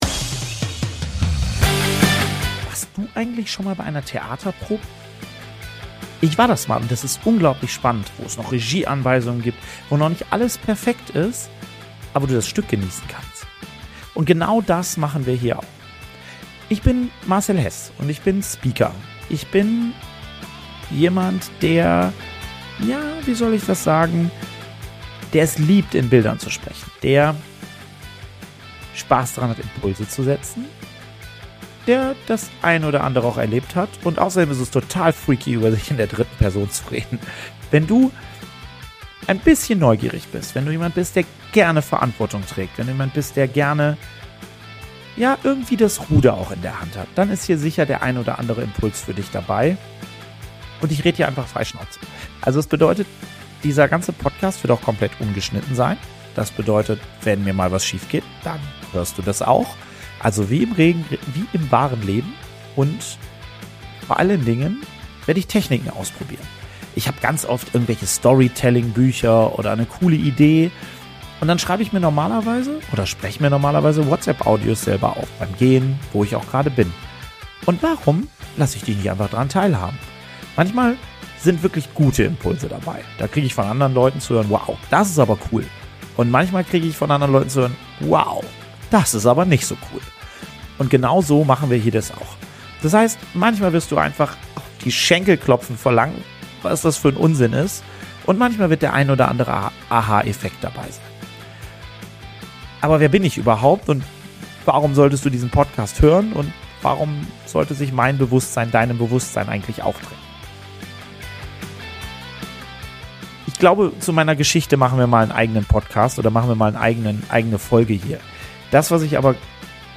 In meinem ungeschnittenen Podcast teile ich,